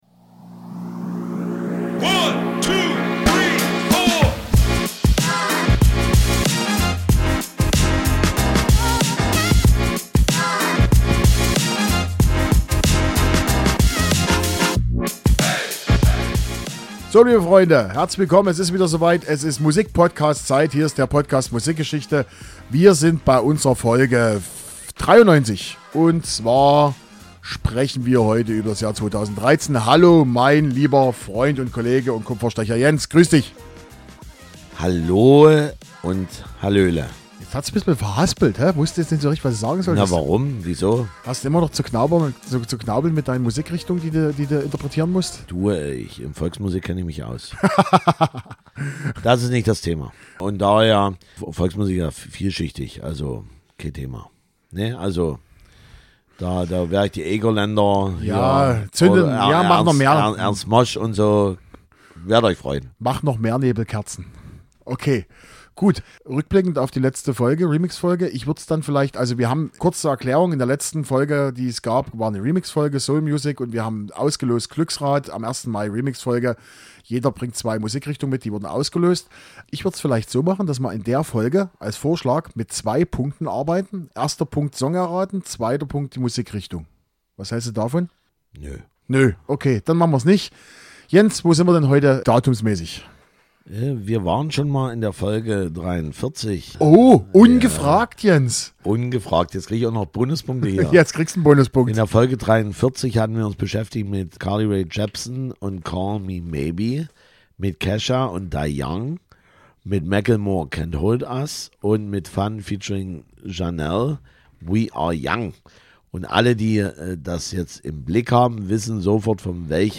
Musikalisch sind wir heute wieder ganz breit gefächert. Deutscher Hip Hop, Rockpop, elektronische Musik und Electro-Rap - wenn man das so nennen darf - haben wir in dieser Folge für Euch dabei.